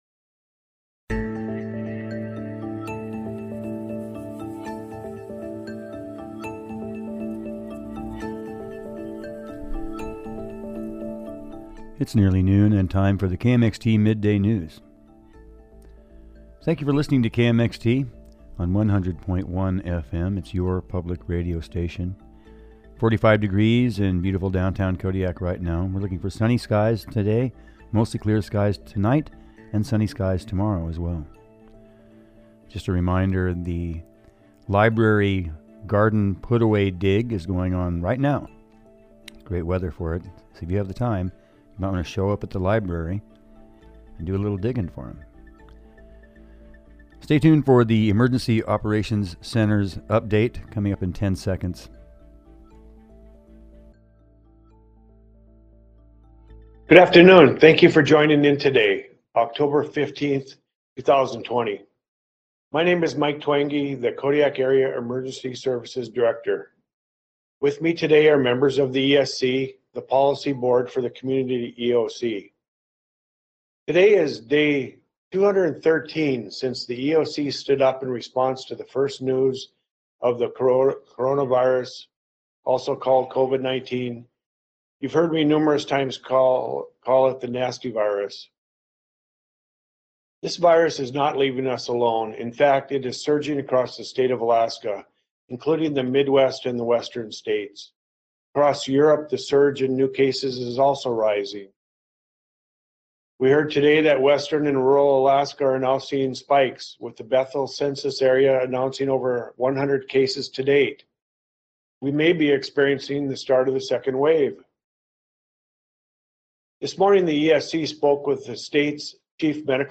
midday news report